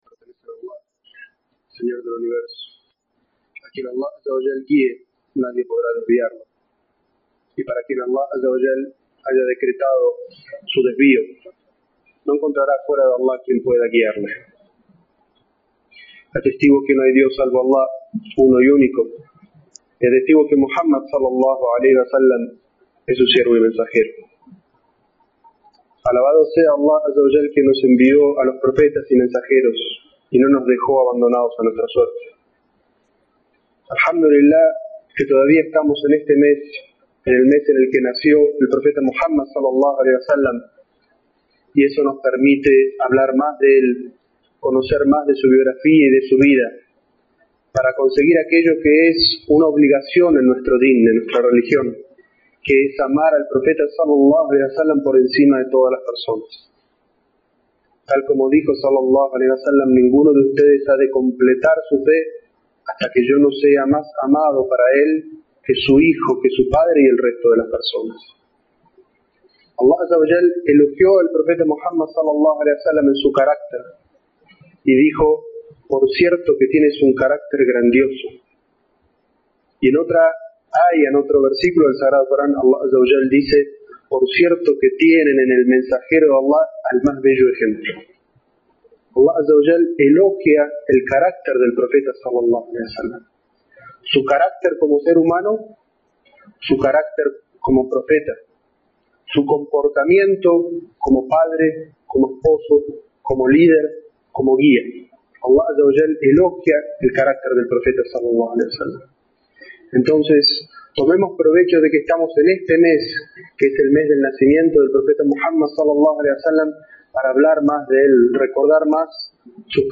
Jutbah para reflexionar sobre las cualidades del Profeta Muhammad, que la paz y las bendiciones de A